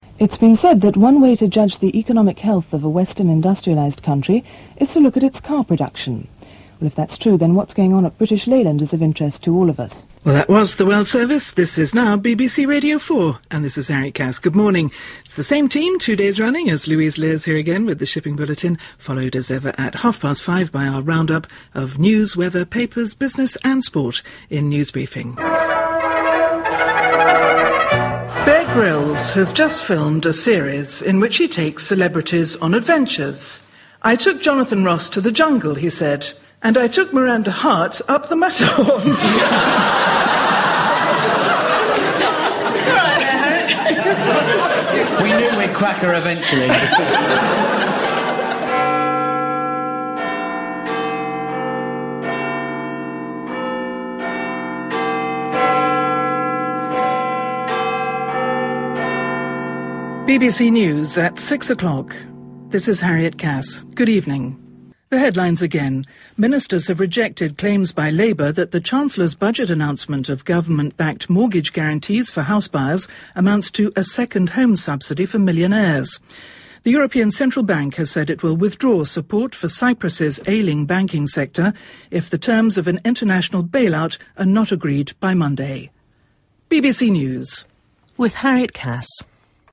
The notes that I kept at the time show that one announcer on BBC Radio 4 in 1977 was Harriet Cass. Thirty-six years later Harriet is leaving the Corporation, her final news bulletin at 6 p.m. today.